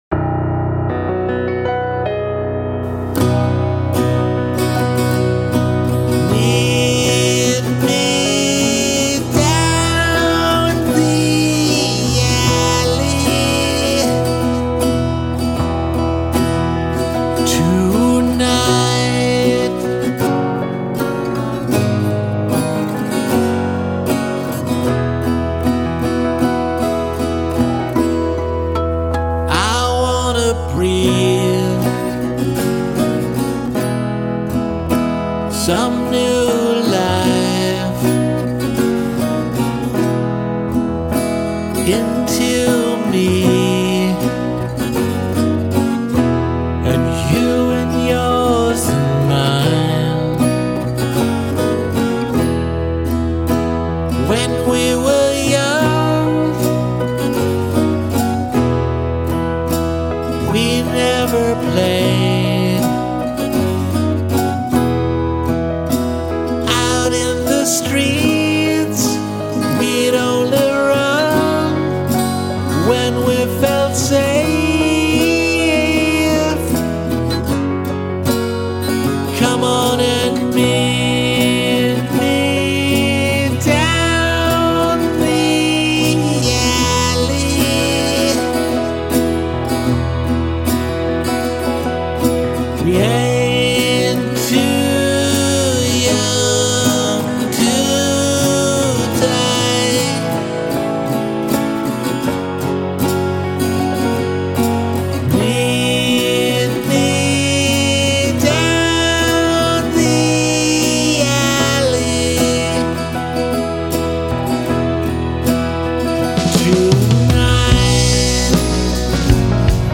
piano, strings and Mellotron
bass
drums